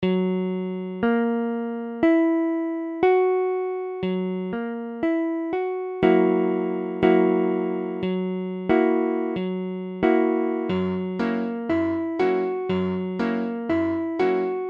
Tablature Gb7.abcGb7 : accord de Sol bémol septième
Mesure : 4/4
Tempo : 1/4=60
A la guitare, on réalise souvent les accords en plaçant la tierce à l'octave.
Sol bémol septième barré II (sol bémol case 2 ré bémol case 4 sol bémol case 4 si bémol case 3 fa bémol case 5 sol bémol case 2)